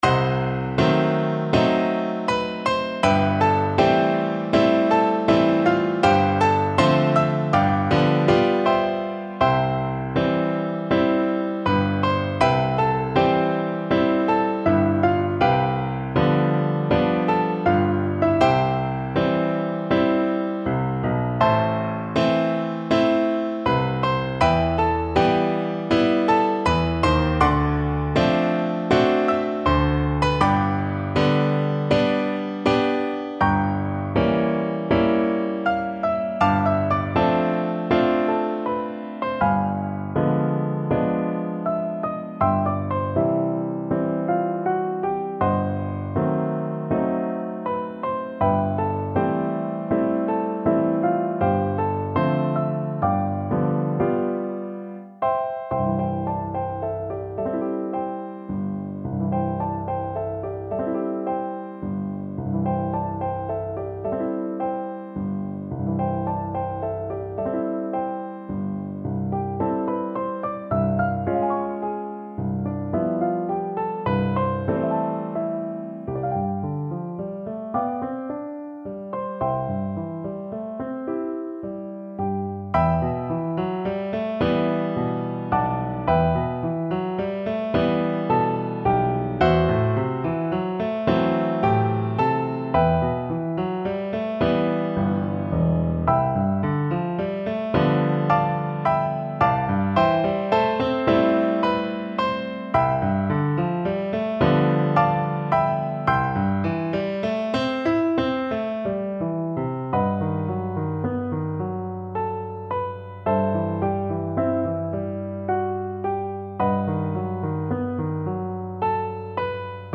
C major (Sounding Pitch) (View more C major Music for Tenor Voice )
4/4 (View more 4/4 Music)
~ = 80 Allegretto moderato, ma non troppo